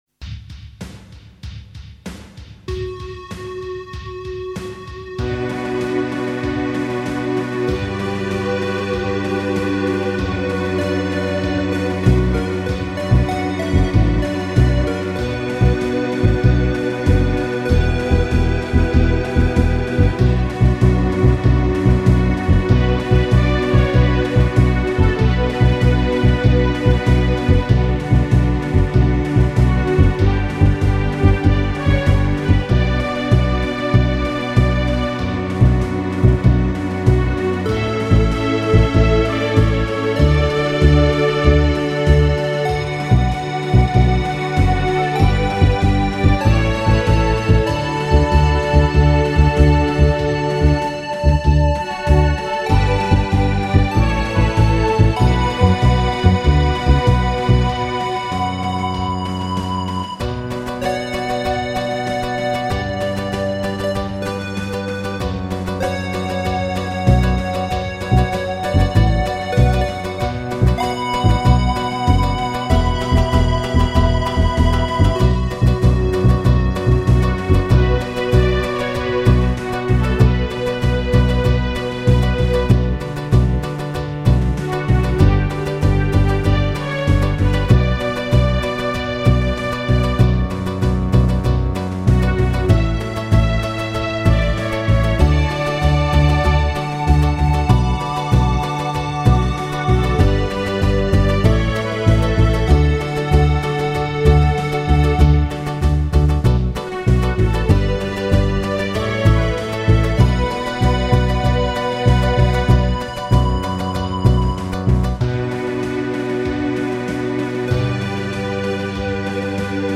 vocal demo